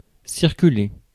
Ääntäminen
IPA : /ˈsɝ.kɪt/ IPA : /ˈsɜː.kɪt/